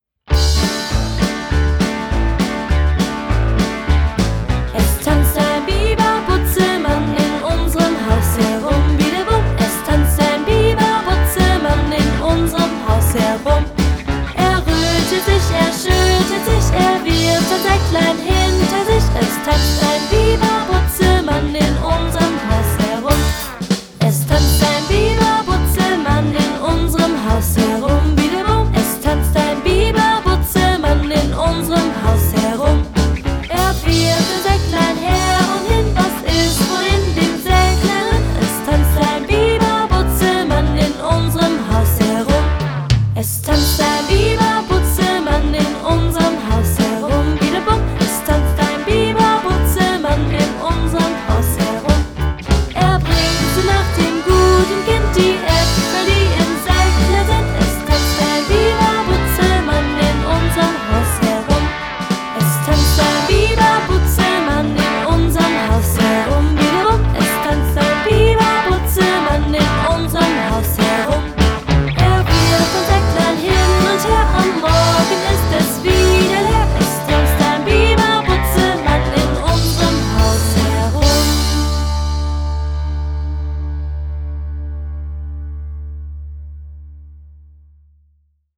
Kinderparty